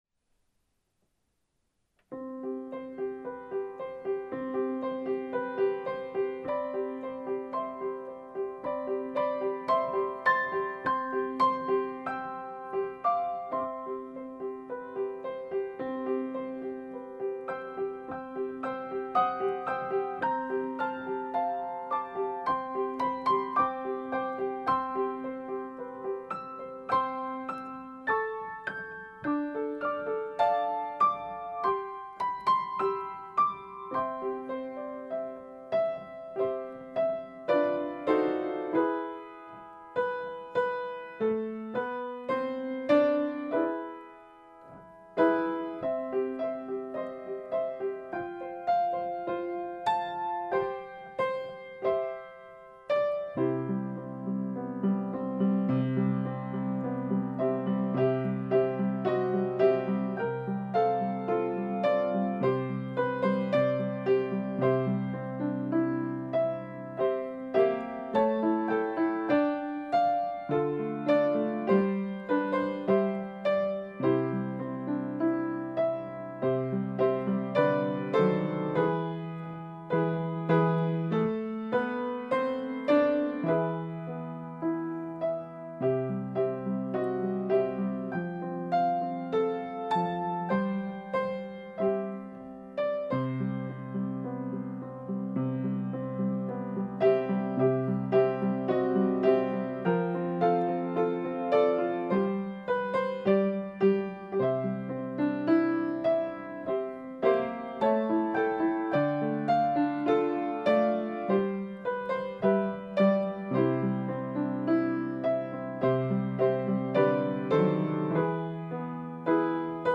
Here are Christmas songs for corporate singing.
5 verses: lowered key C)Download